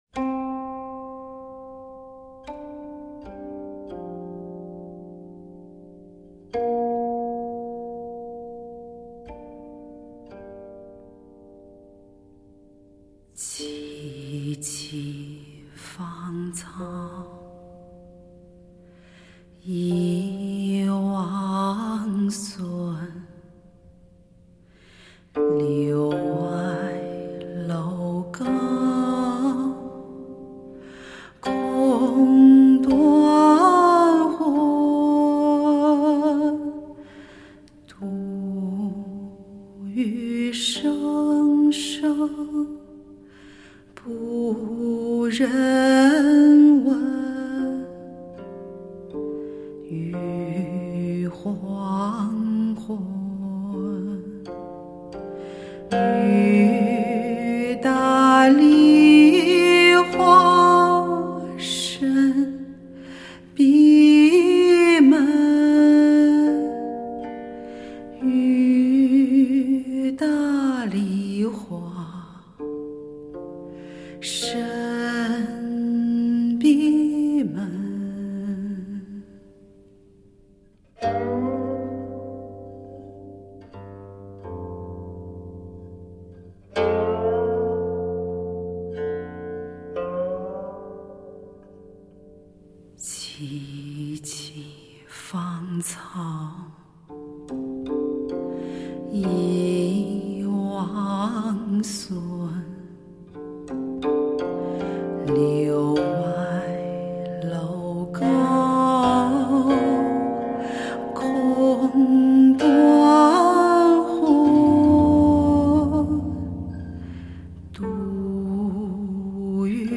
国家大剧院前期拾音 首张女声发烧专辑
古琴与吟唱的珠玉之声飘然入耳
女声音色温暖至极，体积庞大，采用Neumann传奇的M150胆咪拾取，配合Neve8801Pre前级放大。
而演奏用的这张古琴，大胆采用稀有的Royer履带话筒拾音，制造出不同以往的古琴音色，“古”味十足。
女声/琴